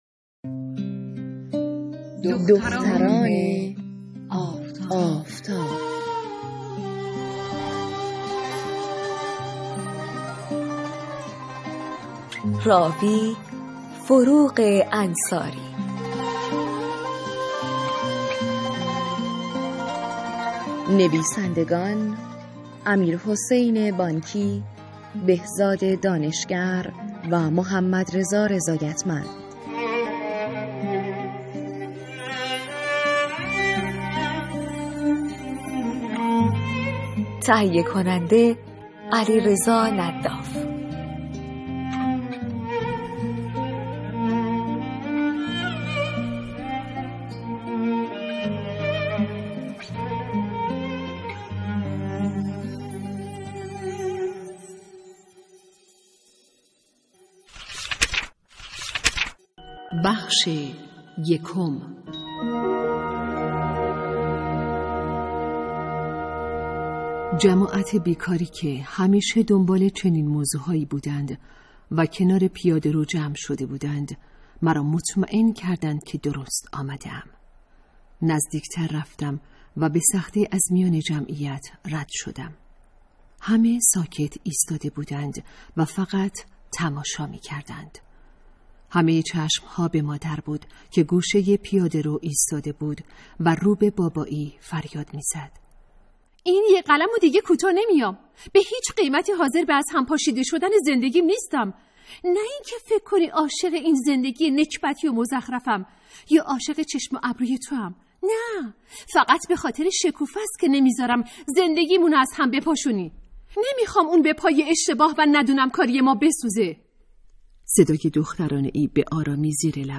کتاب صوتی | دختران آفتاب (01)